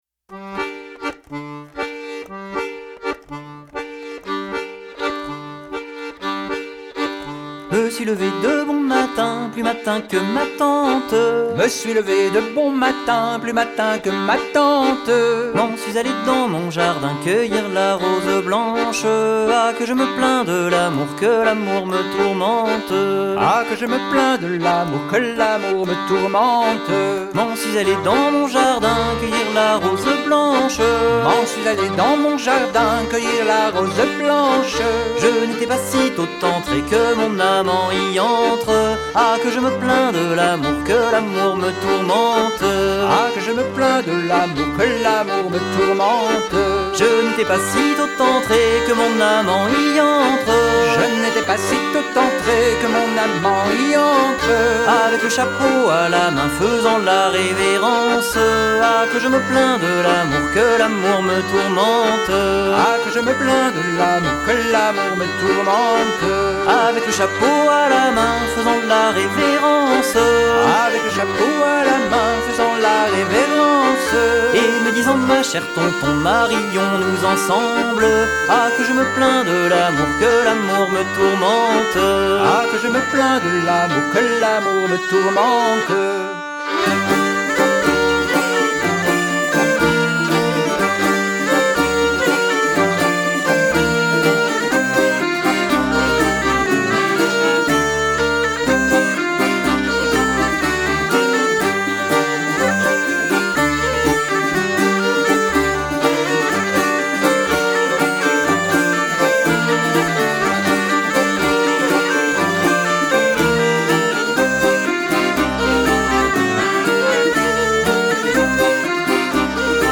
bouzouki & chant
violon & chant
trad wallon